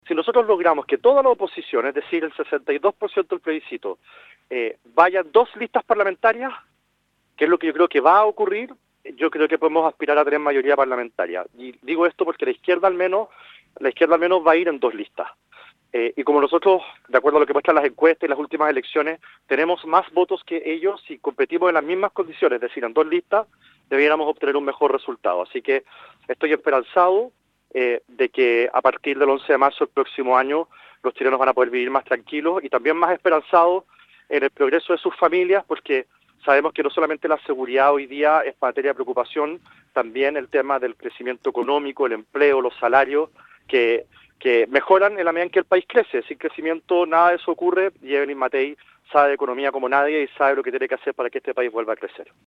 Sobre la expectativa en el Parlamento, el dirigente gremial estimó que si la oposición compite en dos listas, podría aspirar a una mayoría parlamentaria.